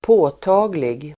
Uttal: [²p'å:ta:glig]